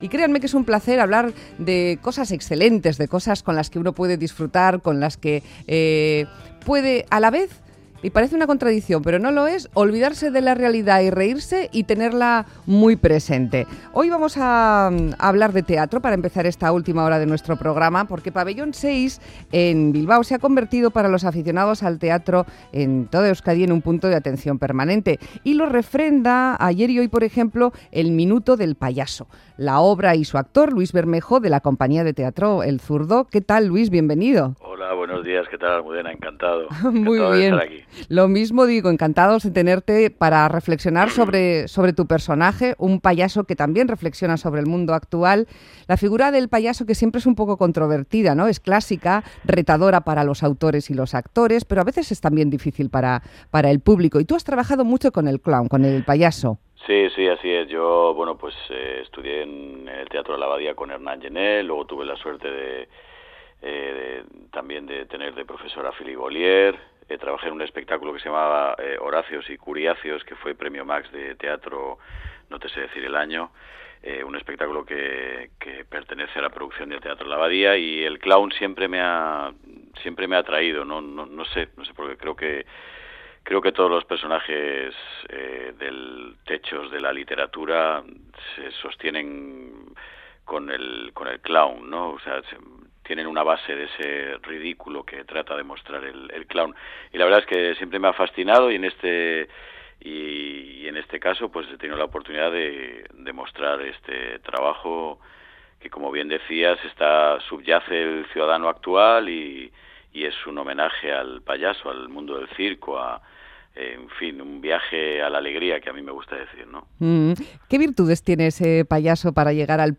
Charlamos en entrevista con el actor Luis Bermejo, protagonista del intenso trabajo “El minuto del payaso” en Pabellón 6 de Bilbao Zorrozaurre | Radio Euskadi